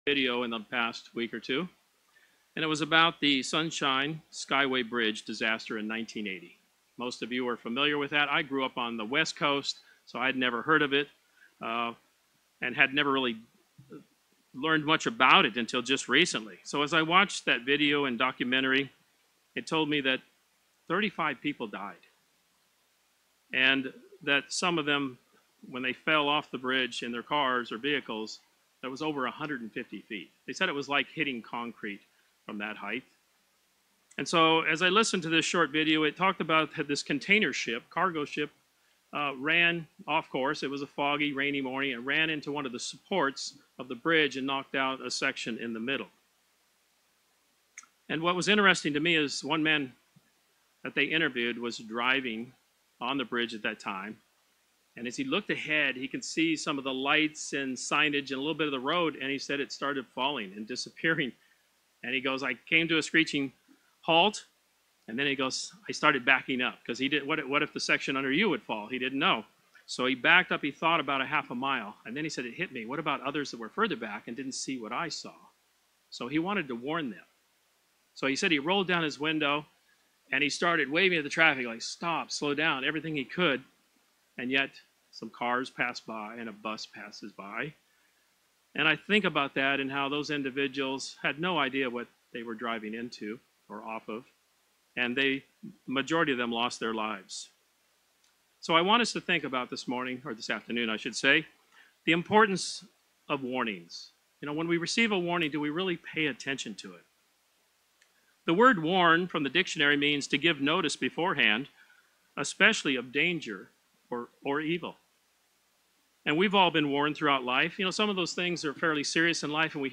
One of the guidelines that Christians should not overlook, is the command to be holy! This sermon focuses on three points that stress the importance of understanding and growing in holiness.